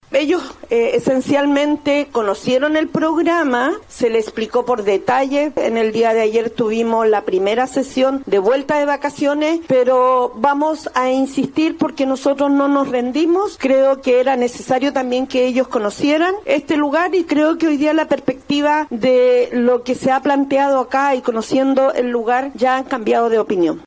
La alcaldesa de La Pintana, Claudia Pizarro, señaló que el concejo sí conoció el programa y que tuvieron los detalles en la sesión.